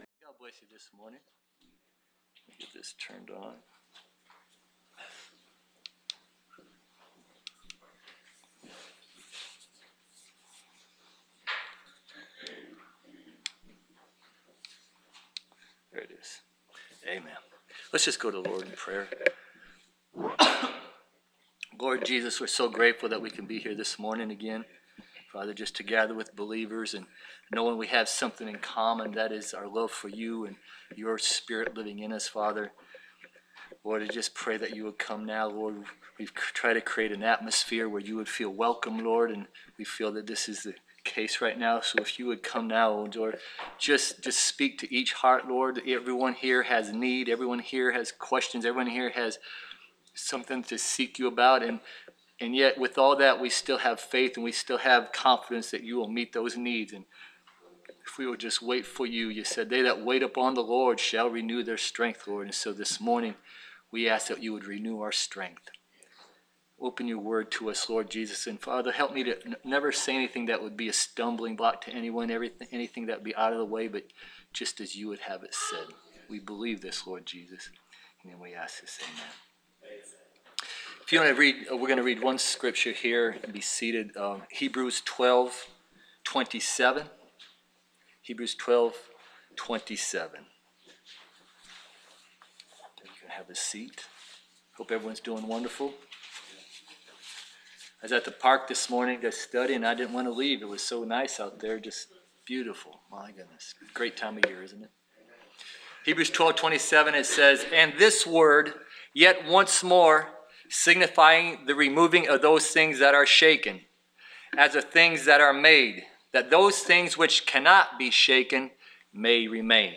Preached November 7, 2021